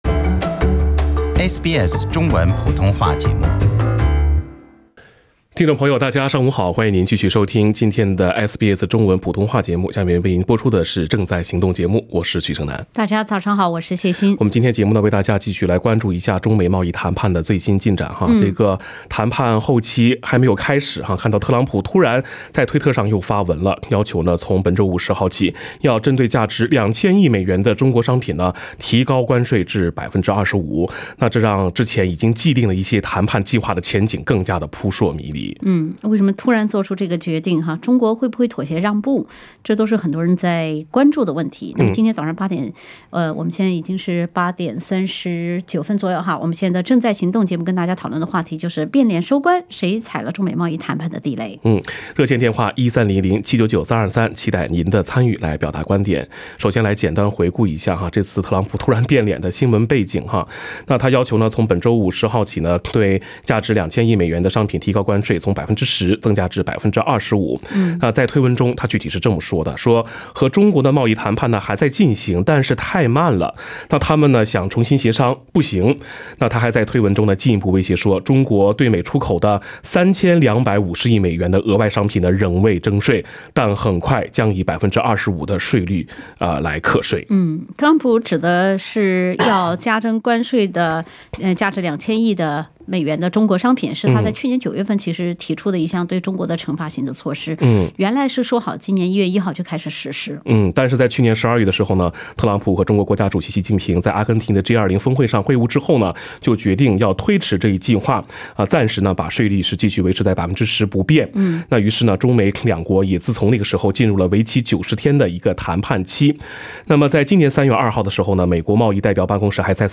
时政热线节目《正在行动》逢周三上午8点30分至9点播出。